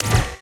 SCIMisc_Sci Fi Shotgun Reload_03_SFRMS_SCIWPNS.wav